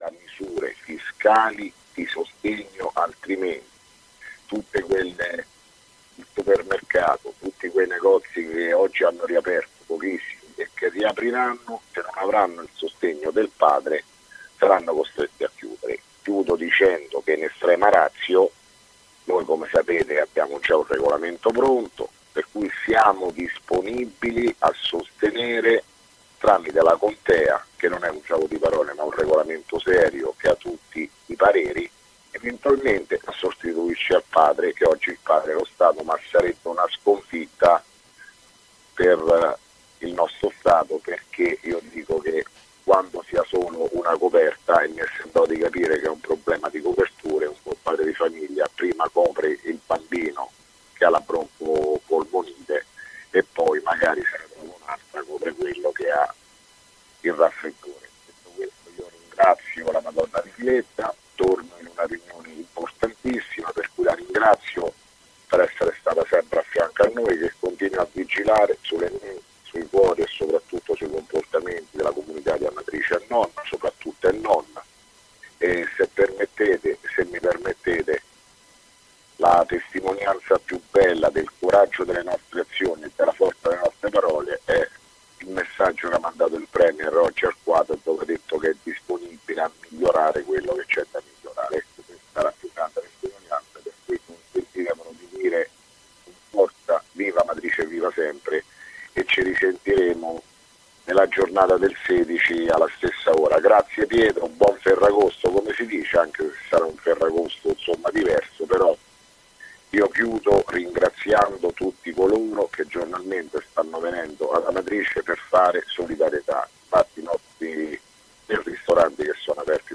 Di seguito il messaggio audio del Sindaco Sergio Pirozzi del 14 agosto 2017